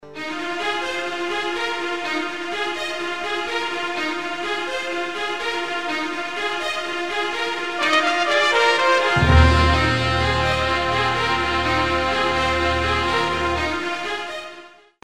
Documentary 05c